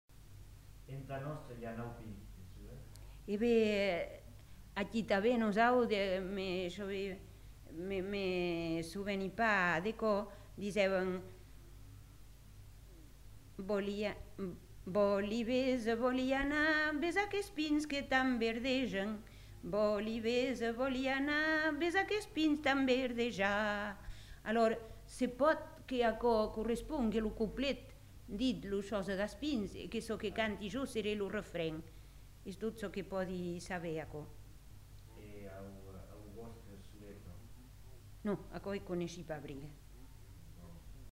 Aire culturelle : Marmandais gascon
Lieu : Tonneins
Genre : chant
Effectif : 1
Type de voix : voix de femme
Production du son : chanté
Description de l'item : fragment ; refr.